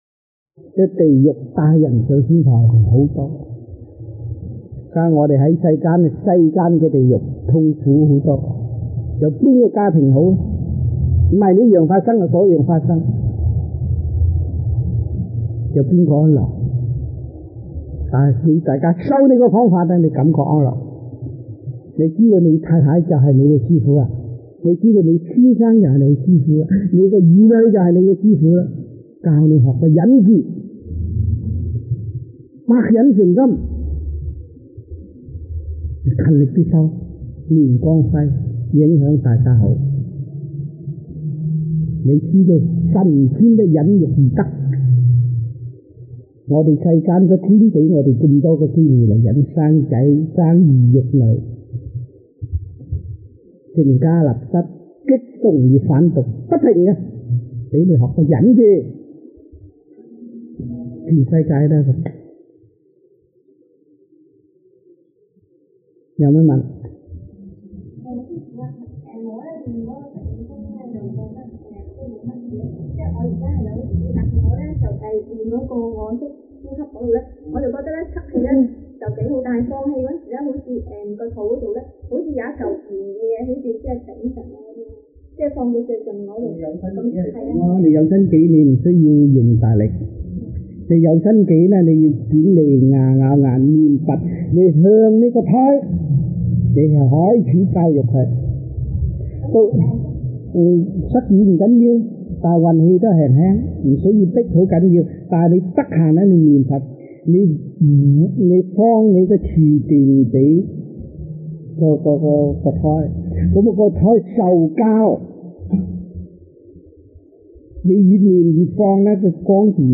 Lectures-Chinese-1988 (中文講座)